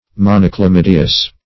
Search Result for " monochlamydeous" : The Collaborative International Dictionary of English v.0.48: Monochlamydeous \Mon`o*chla*myd"e*ous\, a. [Mono- + Gr.
monochlamydeous.mp3